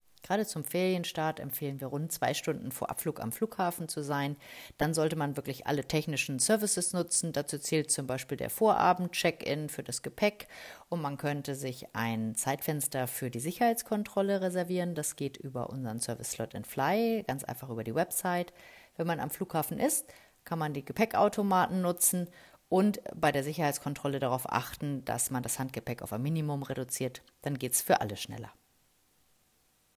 Audio-Statements